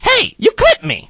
crow_dies_01.ogg